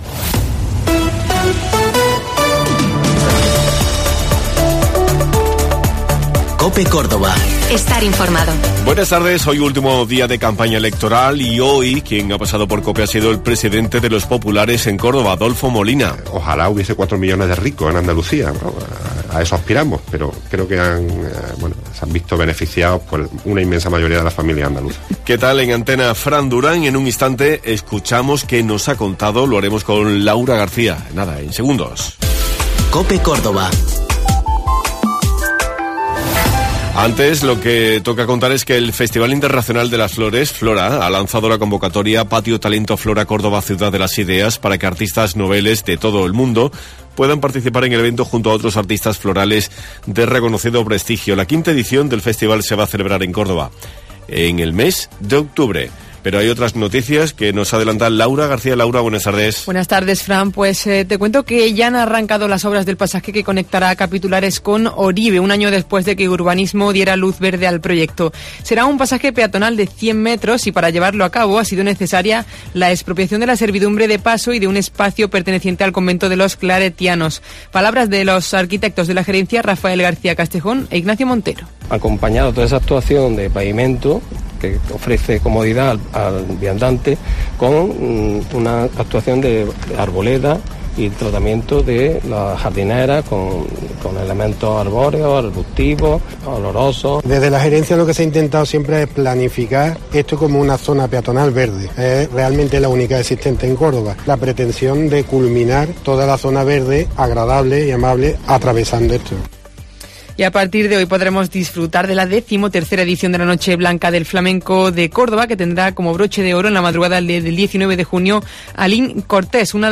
Último día de campaña electoral y hoy ha pasado por los micrófonos de COPE el presidente del Partido Popular de Córdoba, Adolfo Molina.